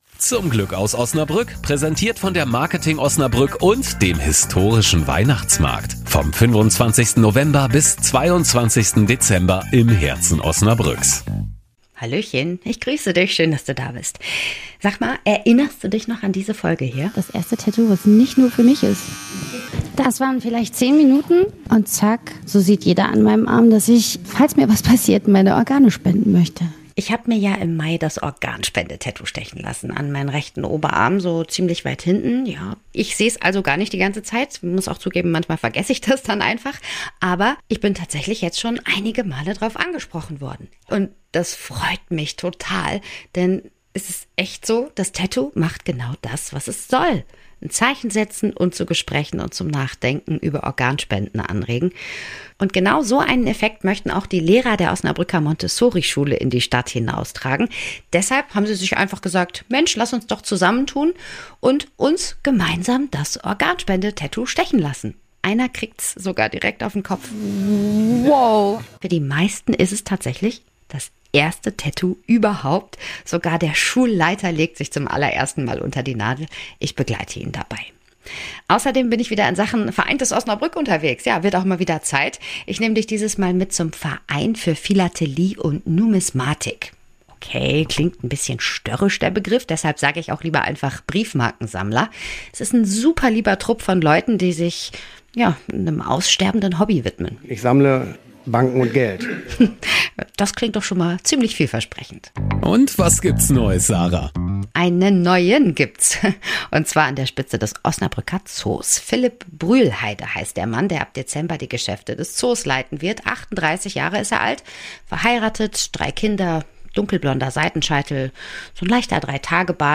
Und diesen inneren Antrieb, den spür ich auch bei jedem einzelnen, als ich sie im LeTigre Tattoo-Stübchen treffe.